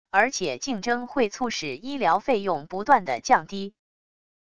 而且竞争会促使医疗费用不断地降低wav音频生成系统WAV Audio Player